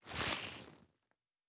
added stepping sounds
Wet_Snow_Mono_05.wav